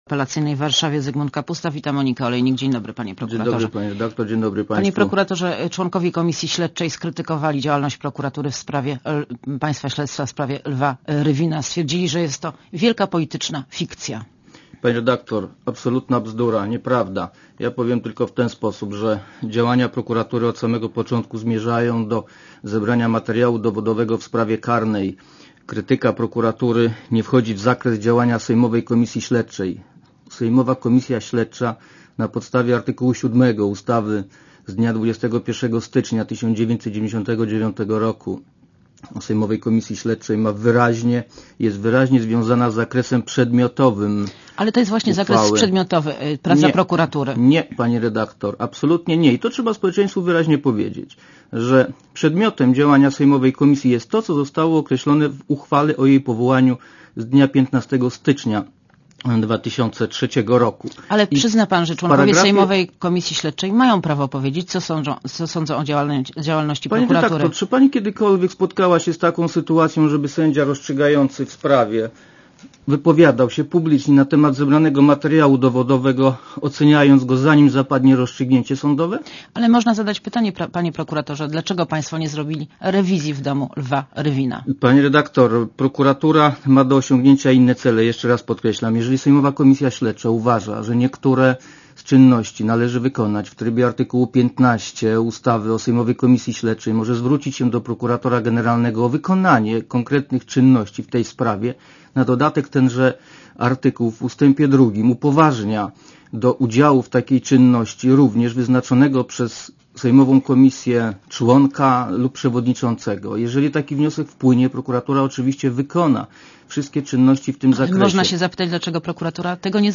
Monika Olejnik rozmawia z Zygmuntem Kapustą - szefem warszawskiej prokuratury apelacyjnej